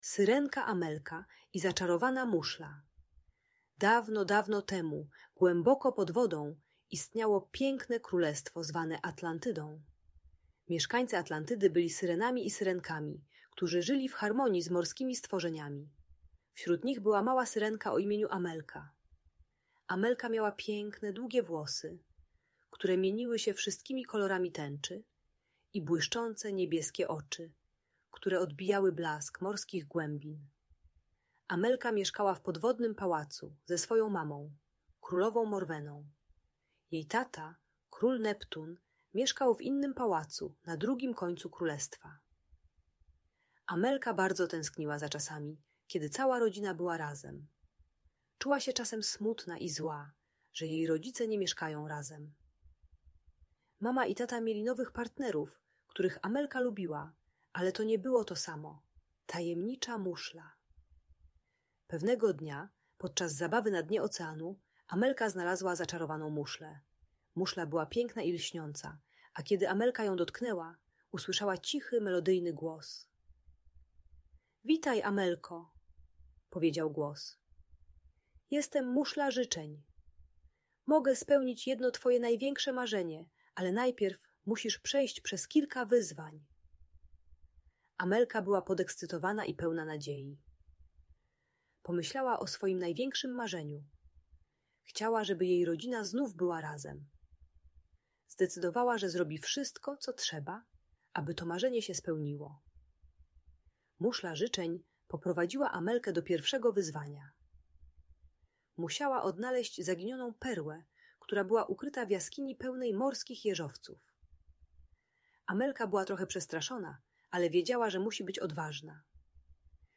Syrenka Amelka i Zaczarowana Muszla - Rozwód | Audiobajka
Audiobook dla dzieci o rozwodzie rodziców - bajka dla dziecka którego rodzice się rozstali. Historia syrenki Amelki dla dzieci 4-7 lat pomaga zrozumieć, że rodzina może być szczęśliwa mimo rozstania rodziców.